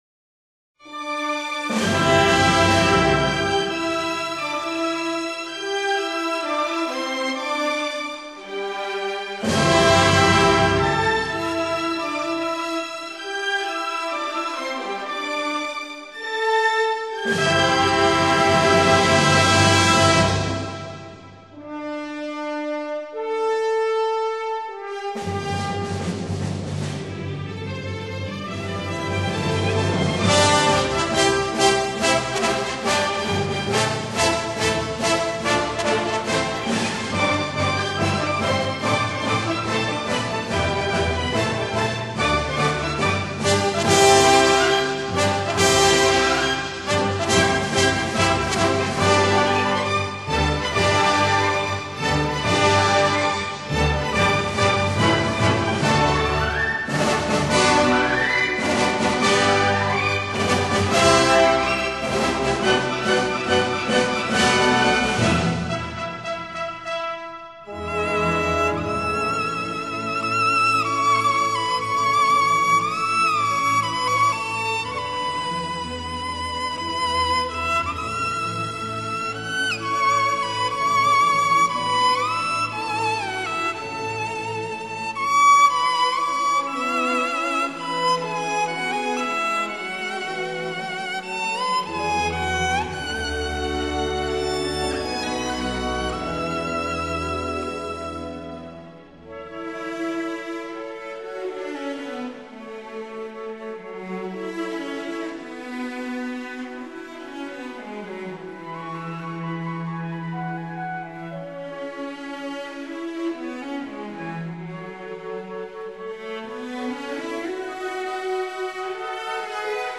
芭蕾舞剧